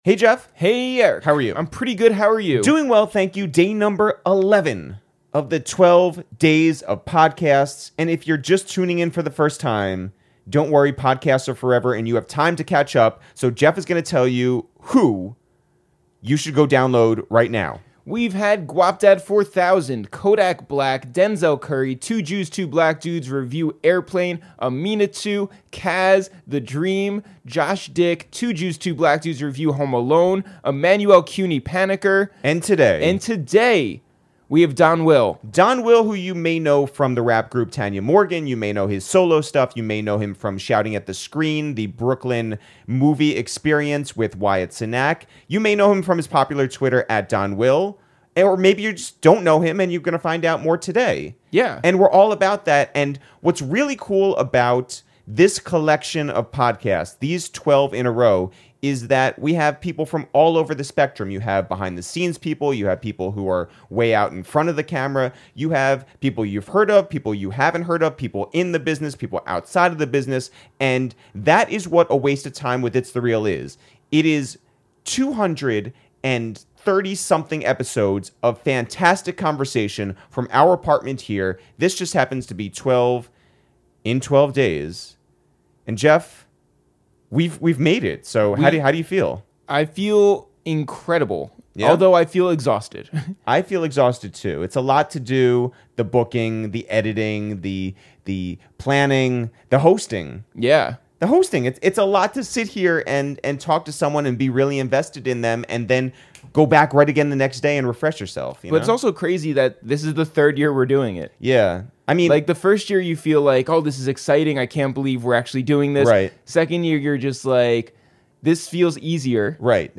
a wide-ranging and personal conversation!